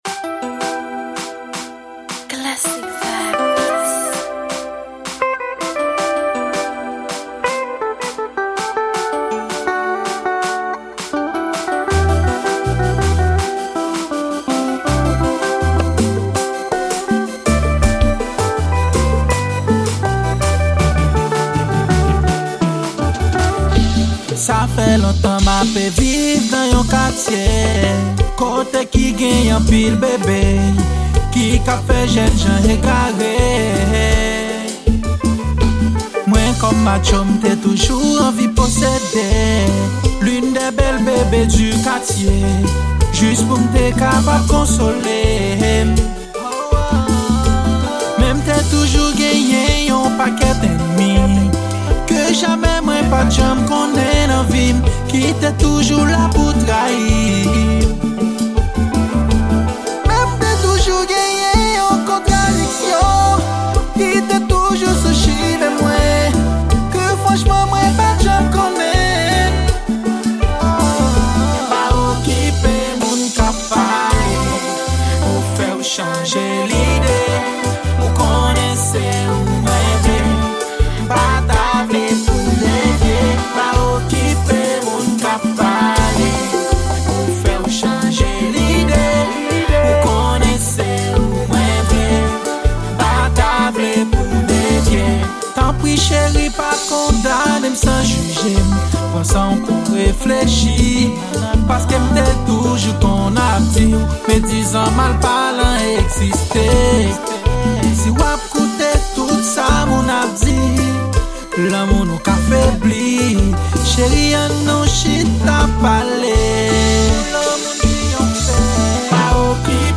Genre: konpa.